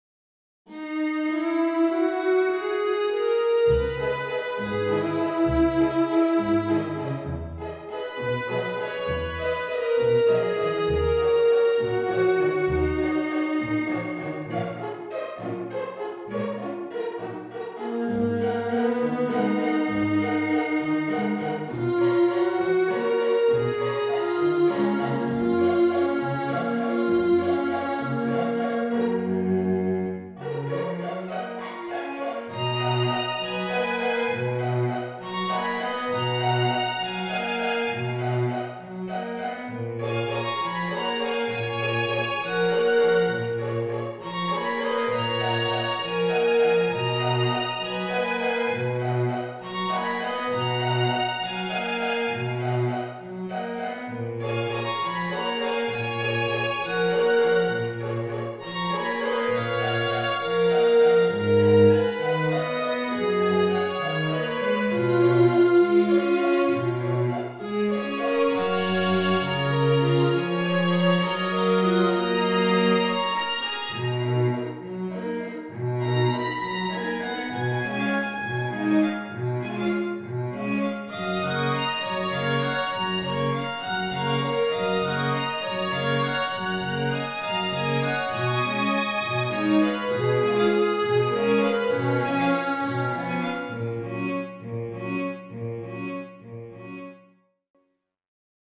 quartetto
quartetto.mp3